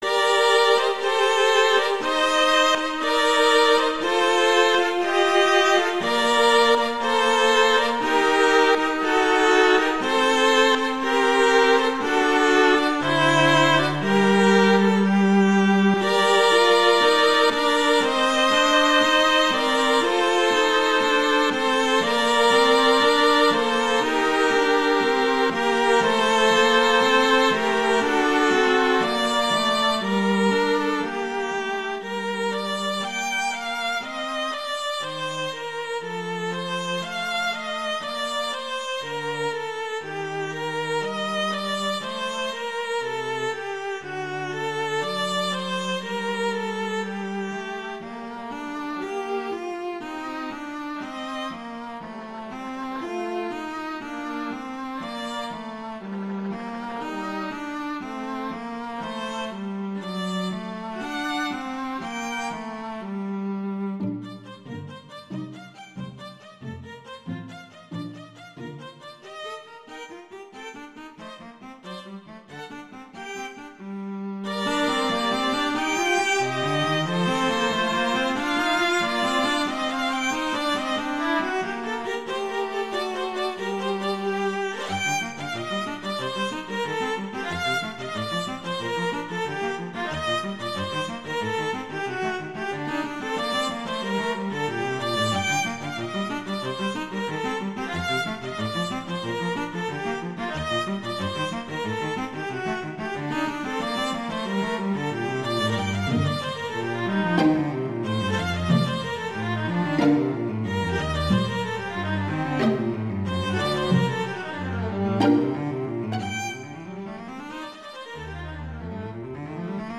classical
G minor
♩=60 BPM